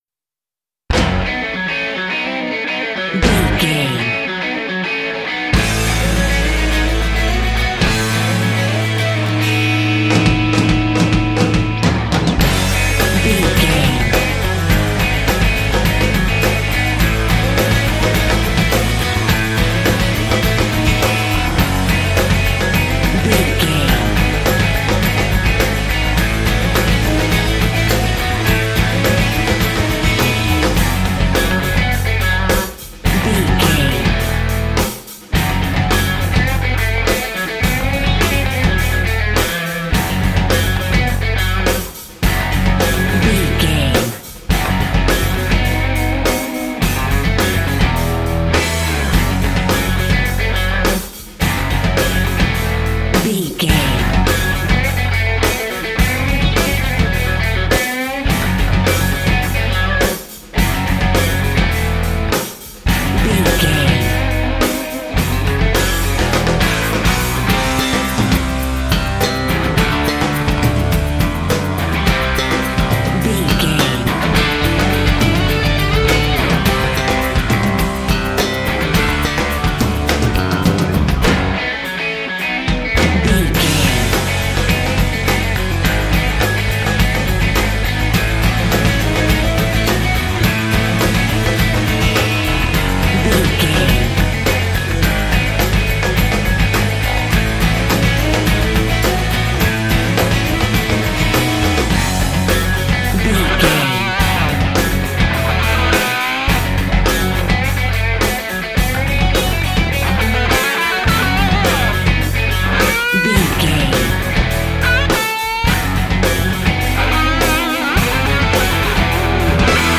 Ionian/Major
drums
electric guitar
bass guitar
hard rock
aggressive
energetic
intense
nu metal
alternative metal